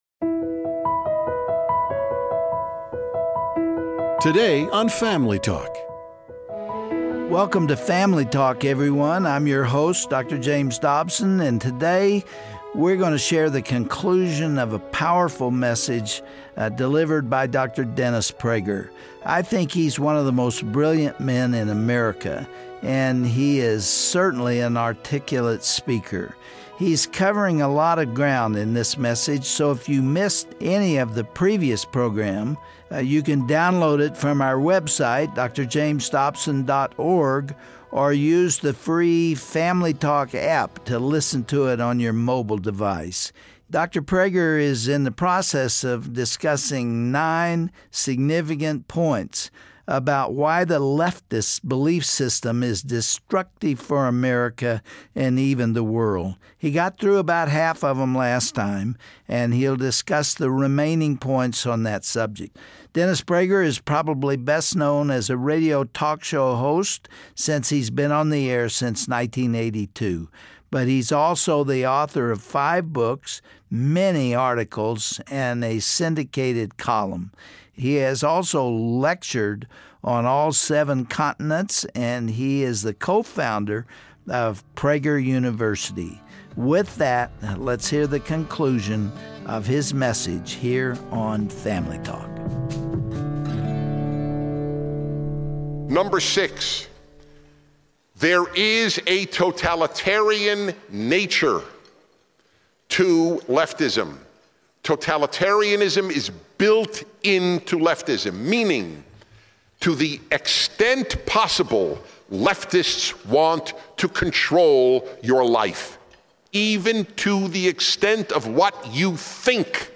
Over the years, America has been leaning more and more to the left, but what does that really mean for our country? Dennis Prager speaks candidly and passionately about the ways leftism debases a culture over time, and he warns that the dangers of leftism arent limited to any particular candidate.